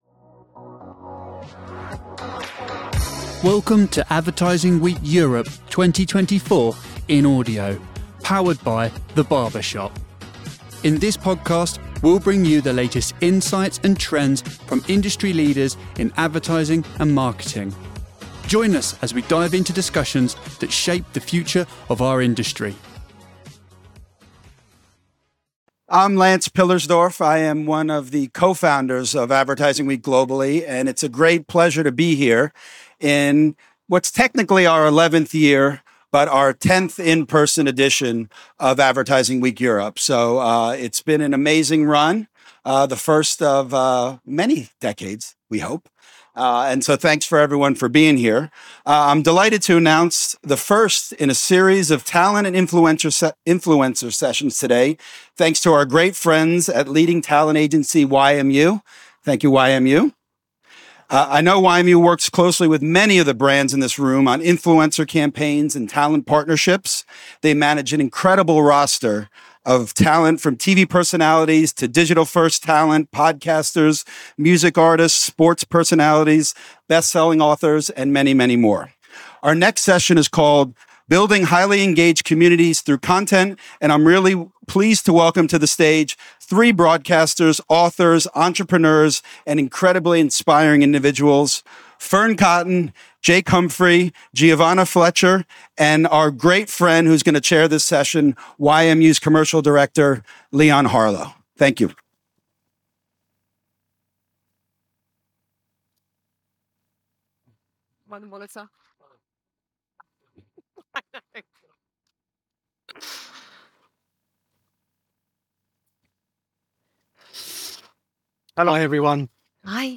Learn from successful podcasters and content creators on building engaged communities through compelling content and effective branding strategies. Fearne Cotton, Giovanna Fletcher, and Jake Humphrey share their experiences in creating popular podcasts and fostering loyal audiences. This session offers insights into content creation, audience engagement, and branding, providing practical tips for building and maintaining vibrant online communities.